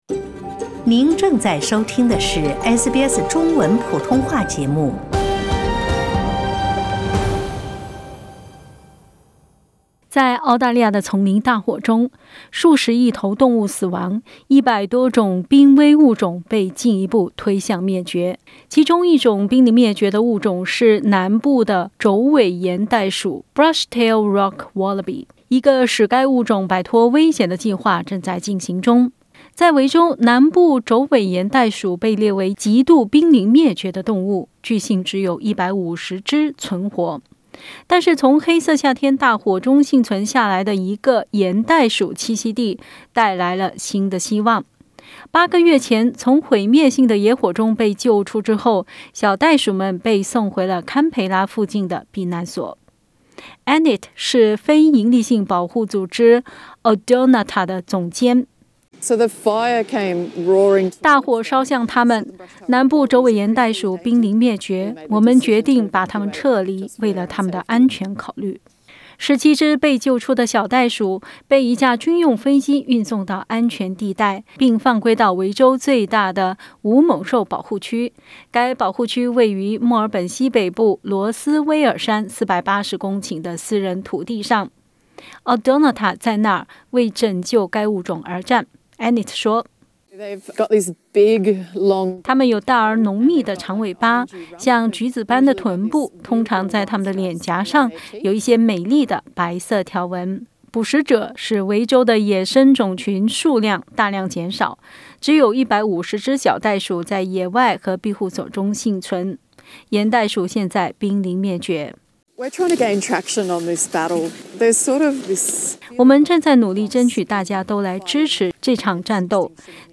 其中一种濒临灭绝的物种是南部帚尾岩袋鼠（Brush-tailed Rock- wallaby）。 点击图片收听详细报道。